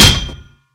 sounds / mob / zombie / metal2.ogg
metal2.ogg